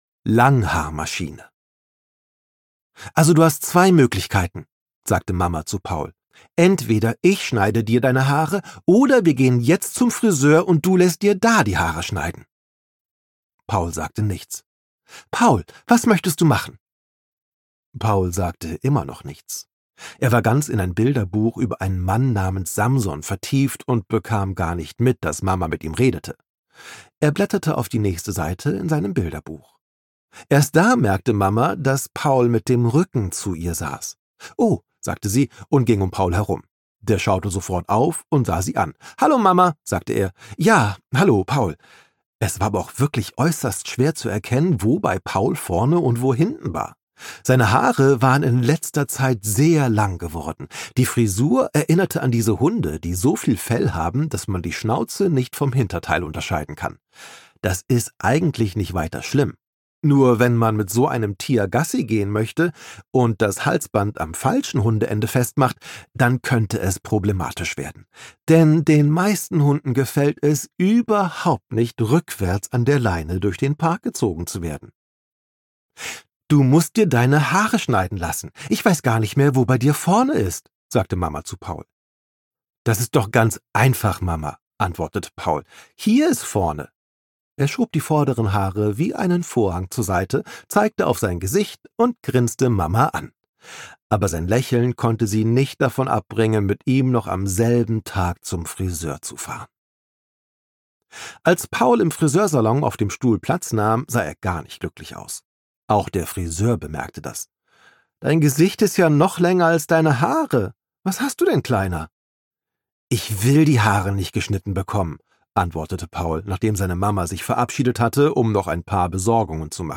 Wenn Riesen reisen - Ralph Caspers - Hörbuch